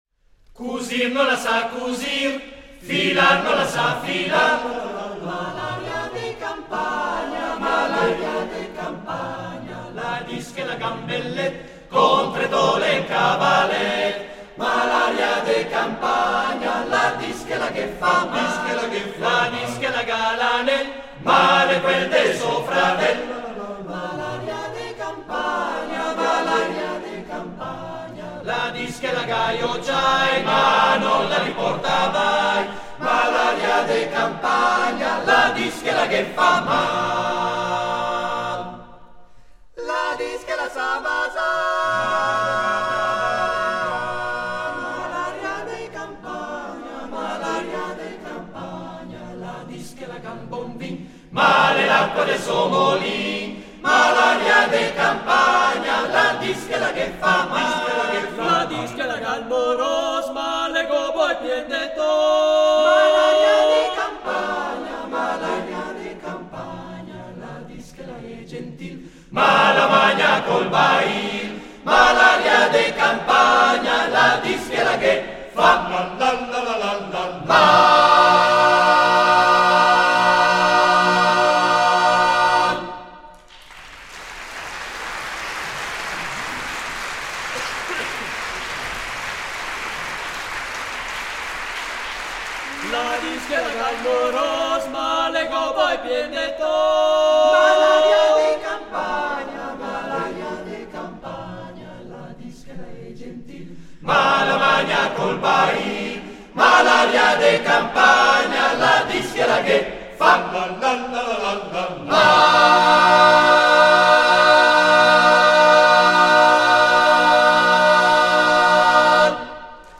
Esecutore: Coro CAI Uget
: Registrazione live